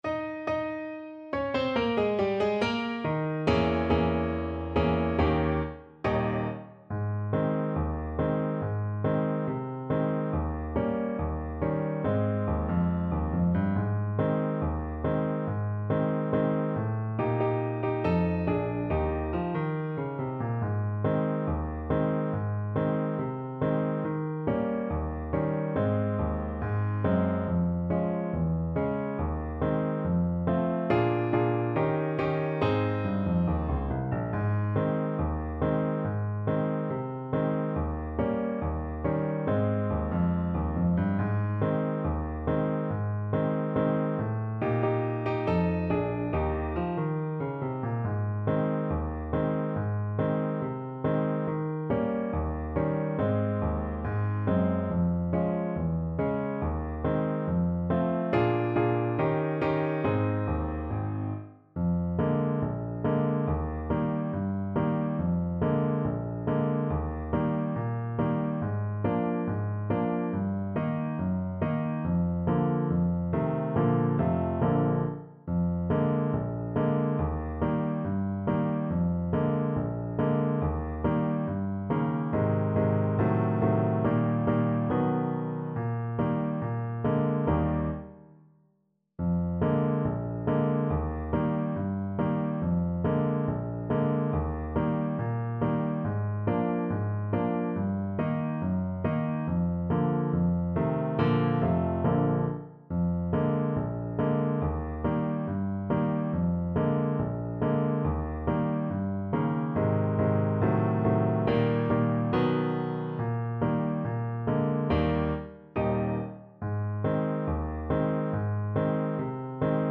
Play (or use space bar on your keyboard) Pause Music Playalong - Piano Accompaniment Playalong Band Accompaniment not yet available reset tempo print settings full screen
2/4 (View more 2/4 Music)
Not fast Not fast. = 70
Ab major (Sounding Pitch) F major (Alto Saxophone in Eb) (View more Ab major Music for Saxophone )